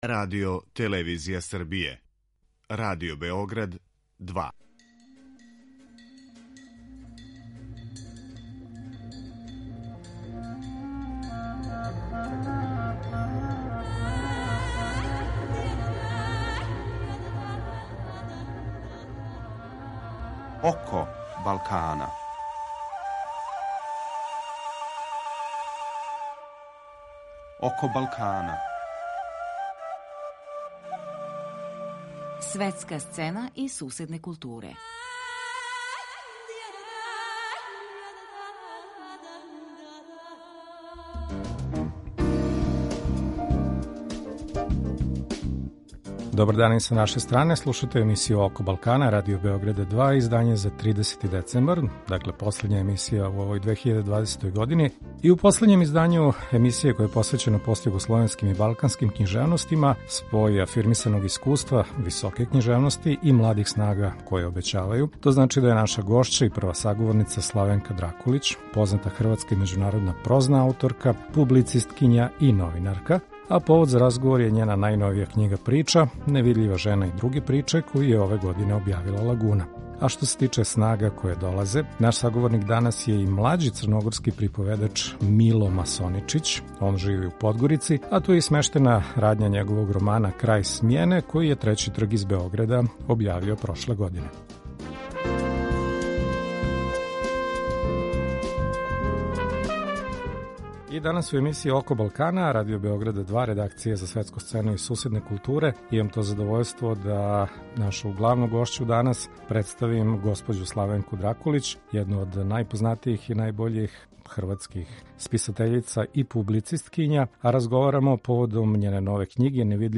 Гости: Славенка Дракулић